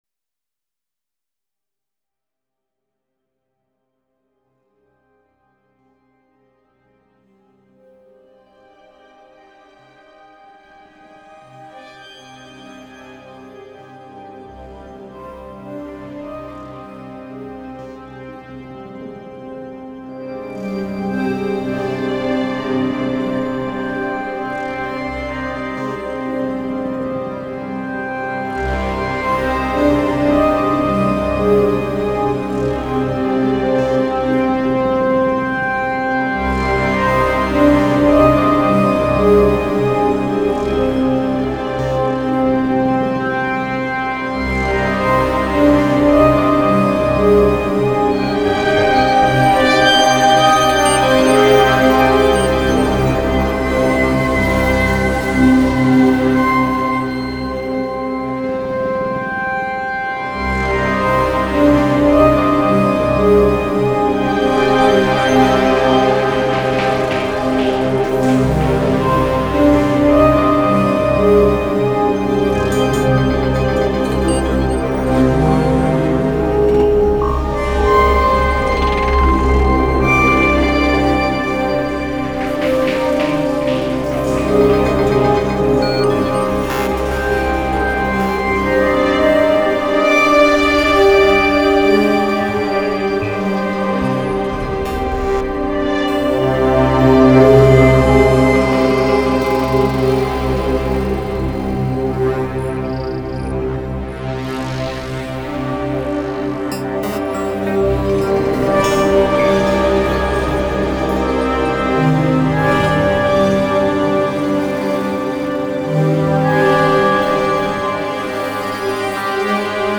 4 Hydrasynth recordings randomly played with OT…
Thanks ! I recorded 4 patches (+“Live Thru” Lemondrop and Blackhole pedal reverb, hence noisy recording) in Octactrack, then played them randomly with rdm lfo on start.
Played at 30 bpm, I didn’t sync anything, didn’t use timestretch.